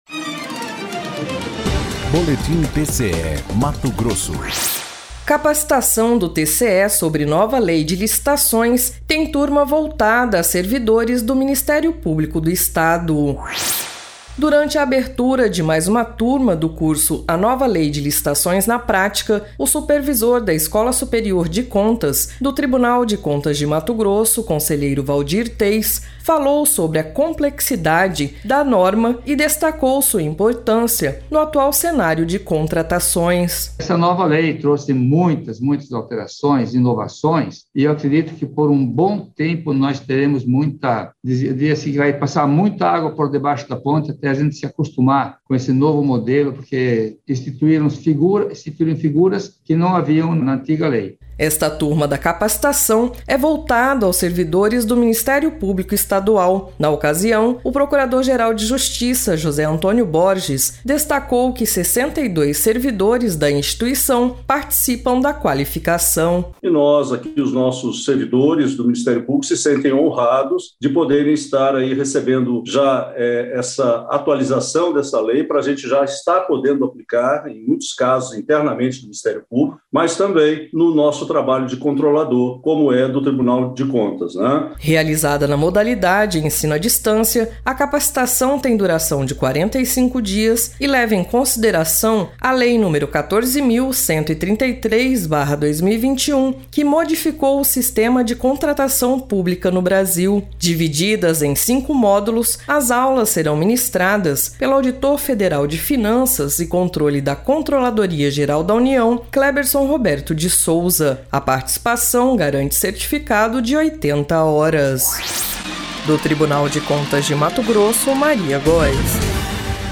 Sonora:  Waldir Teis – conselheiro supervidor da Escola Superior de Contaso TCE-MT
Sonora: José Antônio Borges - procurador-geral de Justiça de MT